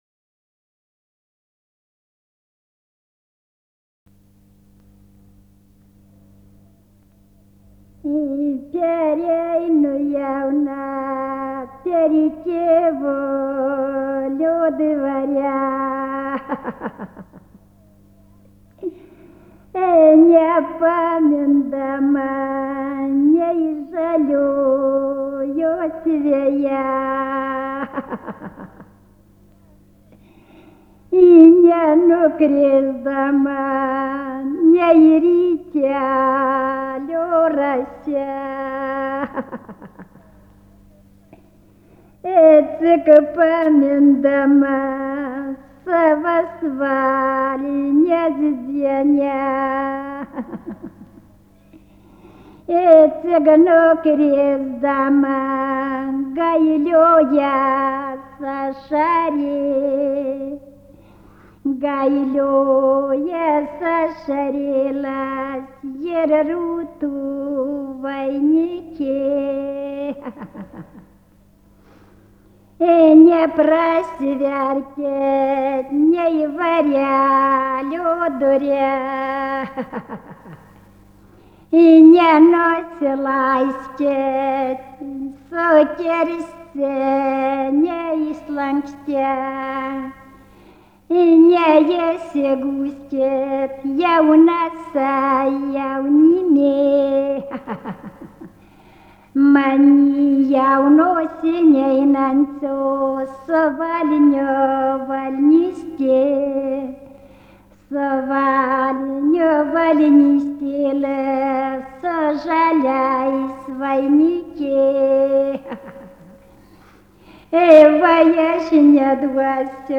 Užumelnyčis
vokalinis